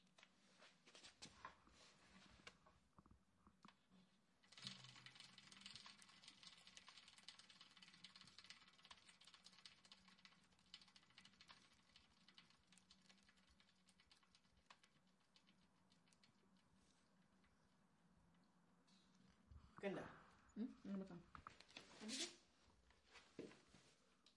施工现场自行车（正面） 2
描述：施工现场自行车声音环境自然周围的现场录音 环境foley录音和实验声音设计。
Tag: 听起来 自行车 建筑 周边环境 网站 现场录音 自然